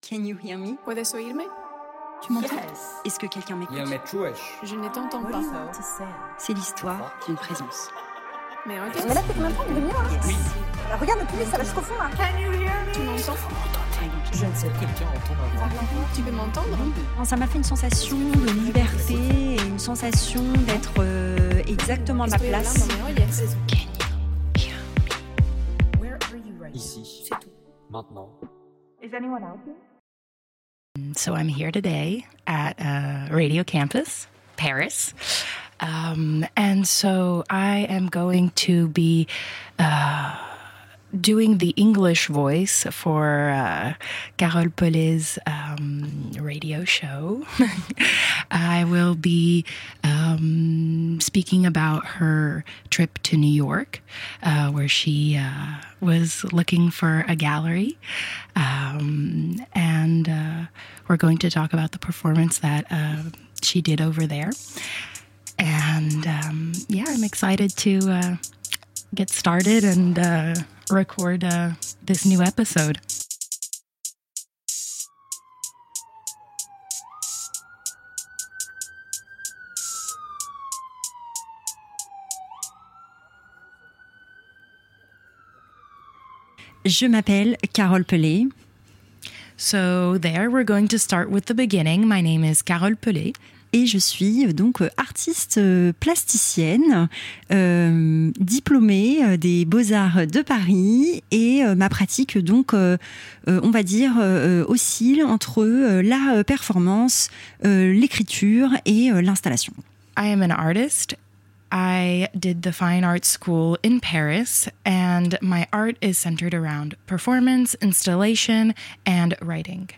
Episode 3 • I’m Looking for a Gallery in New York (French & English) Partager Type Création sonore Découvertes musicales Culture lundi 24 novembre 2025 Lire Pause Télécharger New York.